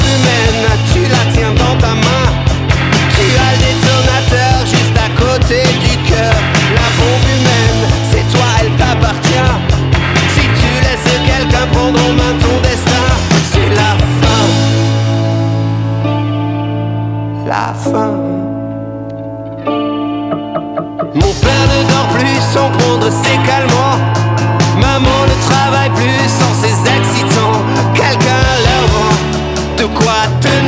0 => "Rock francophone"